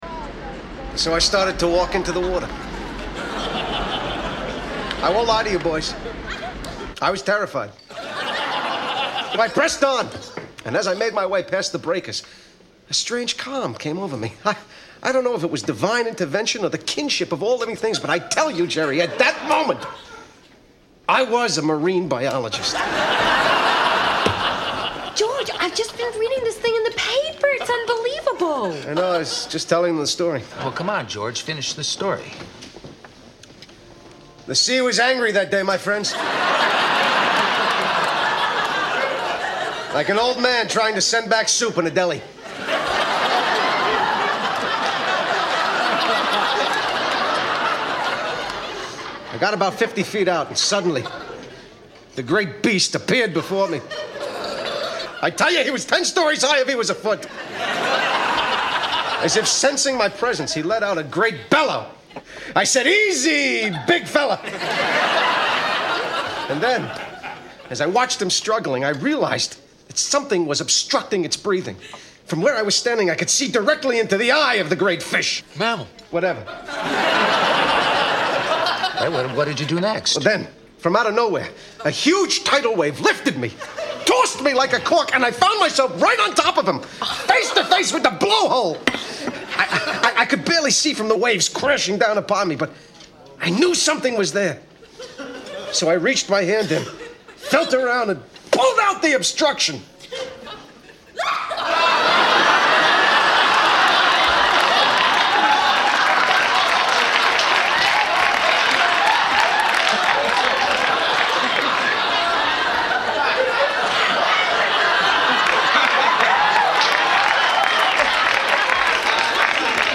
George’s retelling of his experience as a “marine biologist” has the wittiest dialogue in the show’s nine-season run. It’s a perfect scene: George’s verbal cadence, the timing of the “big reveal,” and Kramer’s incredulous reaction and spectacular one-liner.